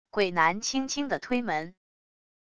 鬼男轻轻的推门wav音频